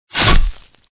CHOMPStation2/sound/weapons/slice.ogg